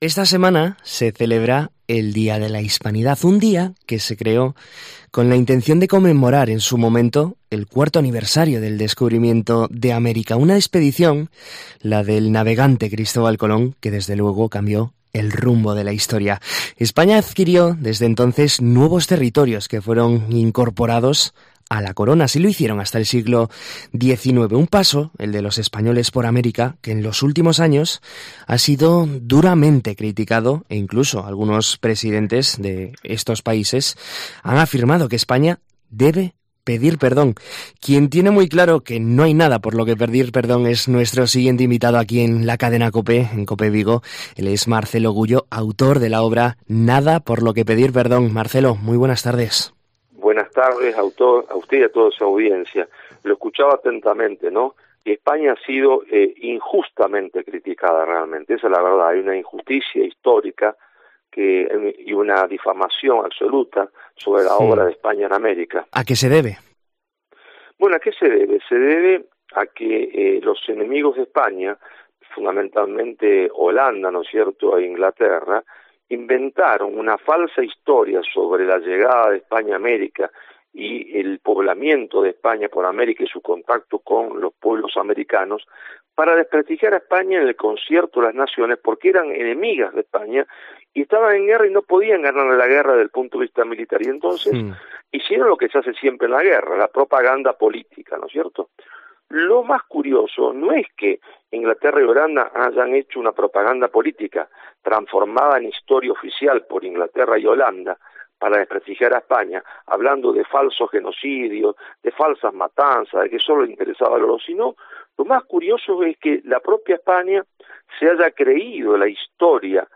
AUDIO: En COPE Vigo charlamos con Marcelo Gullo sobre su nueva obra 'NADA por lo que pedir PERDÓN'